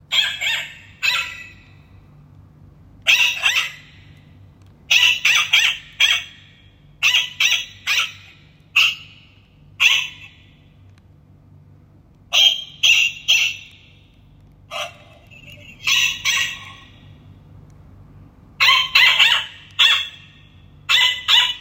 Our clocks don't just tell time, they bring it to life with charming hourly sounds.
Click on any clock below to hear the sound it makes every hour and discover which one fits your style best.